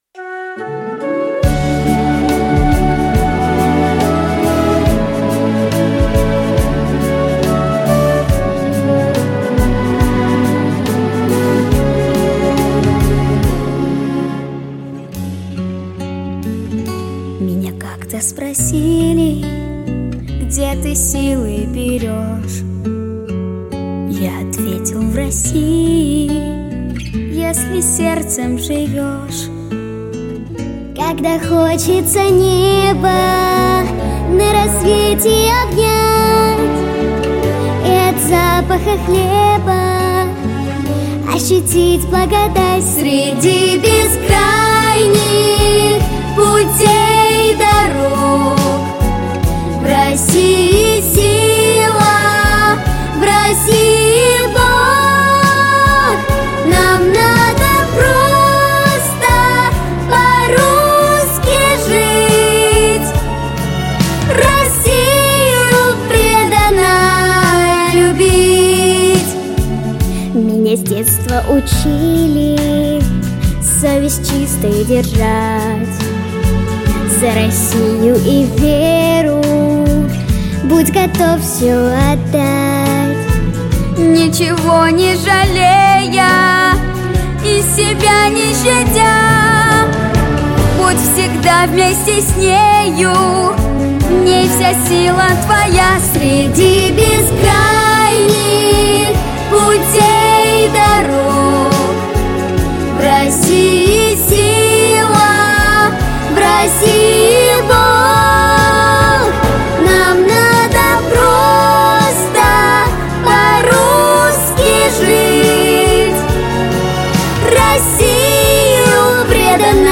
• Категория: Детские песни
патриотическая